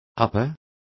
Complete with pronunciation of the translation of uppers.